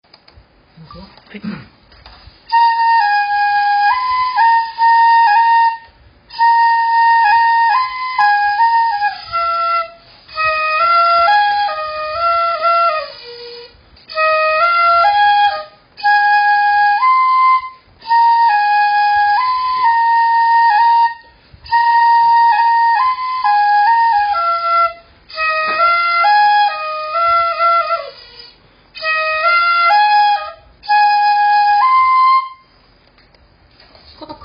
篠笛練習してます！